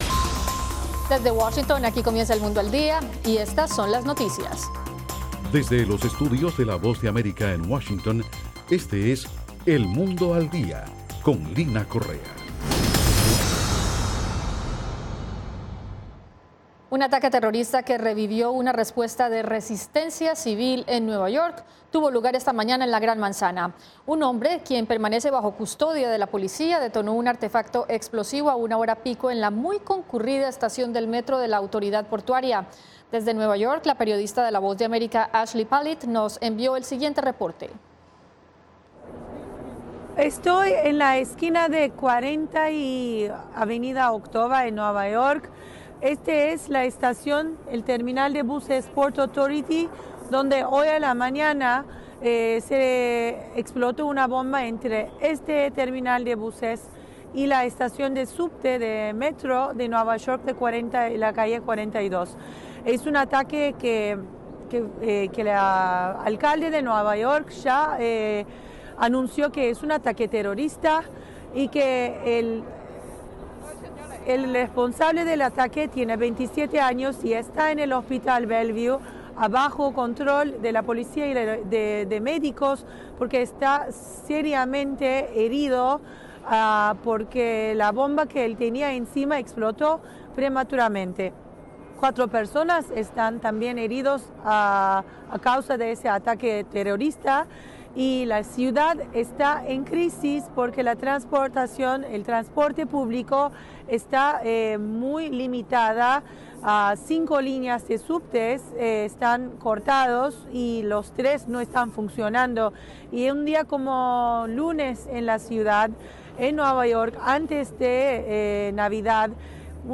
Las noticias del acontecer de Estados Unidos y el mundo con la Voz de América.